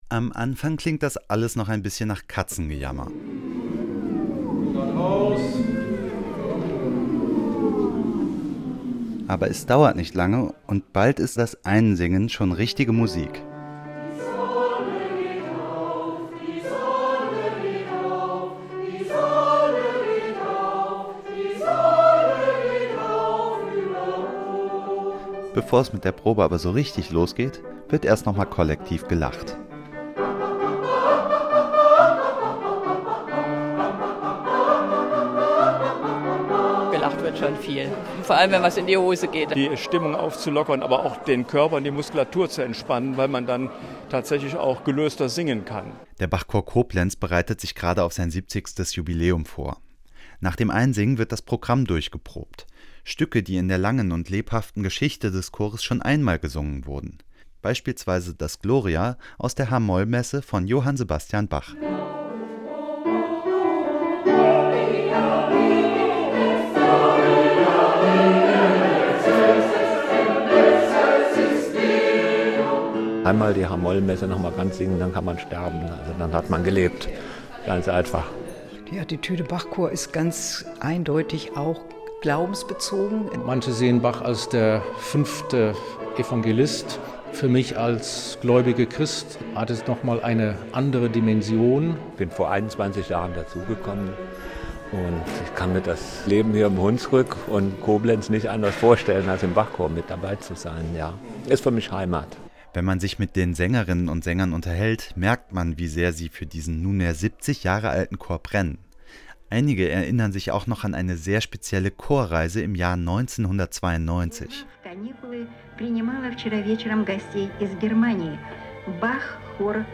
Portrait